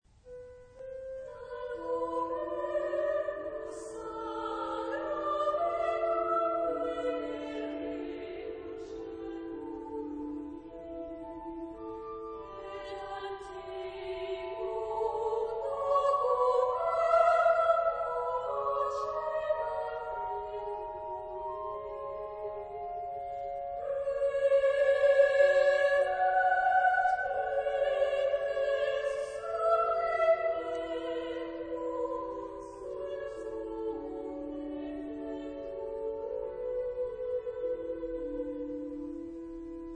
Epoque: 20th century
Genre-Style-Form: Motet ; Sacred
Type of Choir: SA  (2 children OR women voices )
Instruments: Organ (1)
Tonality: E minor